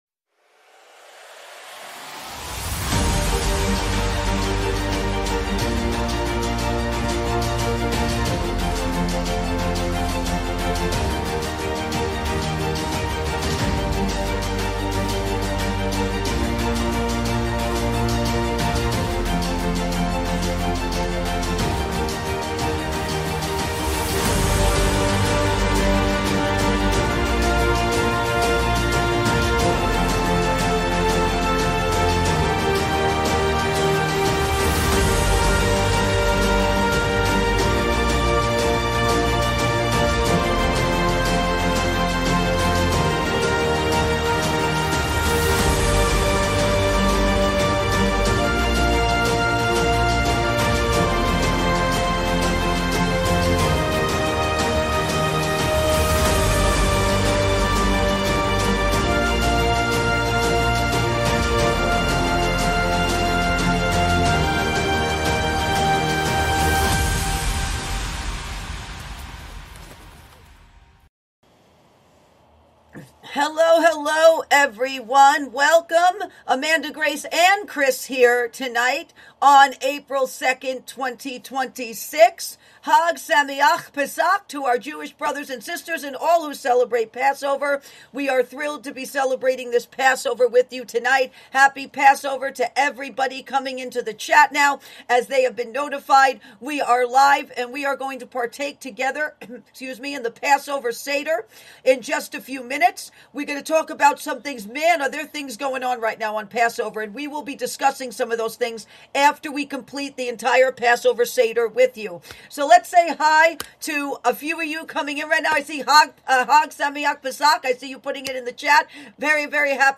LIVE PASSOVER SEDER – APRIL 2, 2026